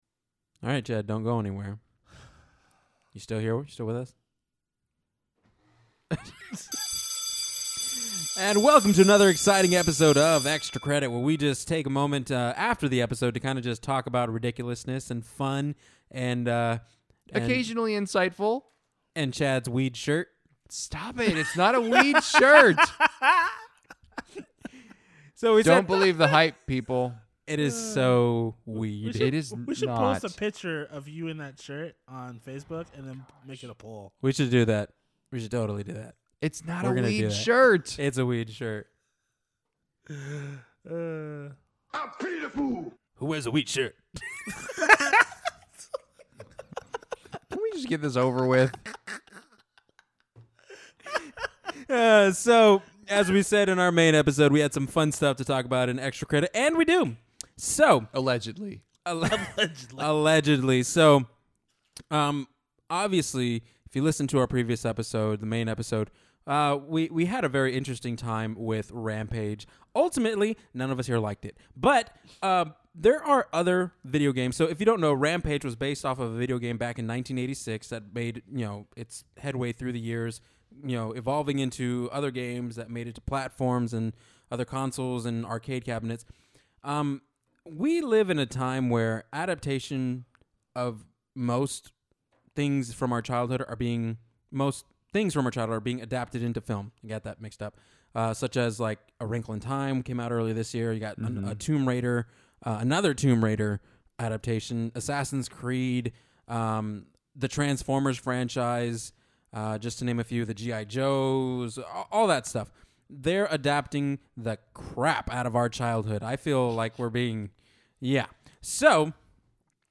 In this week's bonus episode, the guys attempt to find better video games to adapt to film, and, ultimately, fight against the almighty power on the show: The Soundboard! Stick around and join the conversation as we are barely able to contain ourselves!